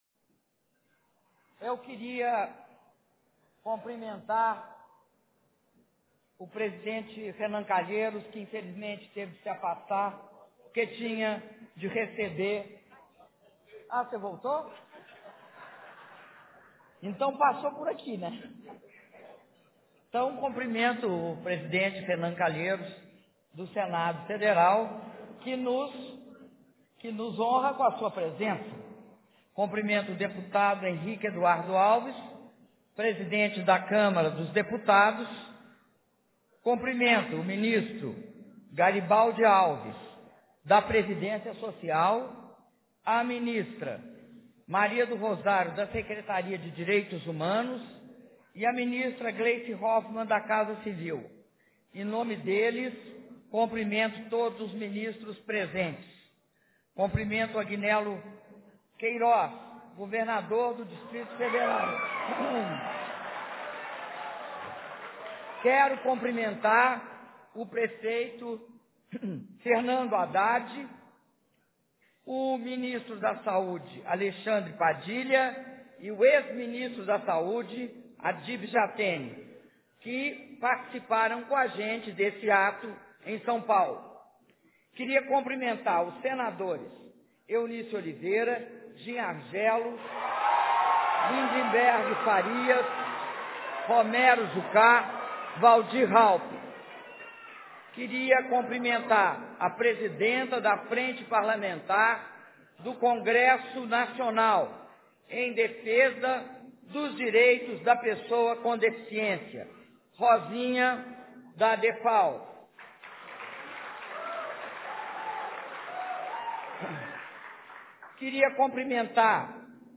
Áudio do discurso da Presidenta da República, Dilma Rousseff, na cerimônia de assinatura do Decreto de Regulamentação da Aposentadoria Especial para a Pessoa com Deficiência - Brasília/DF